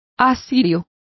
Complete with pronunciation of the translation of assyrians.